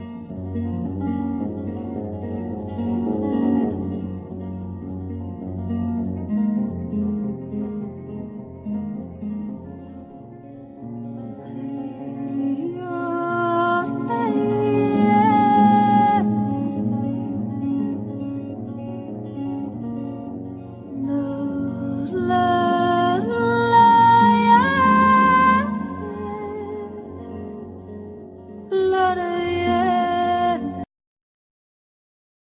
Guitar
Vocals
Soprano saxophone
Drums
Double Bass
Keyboards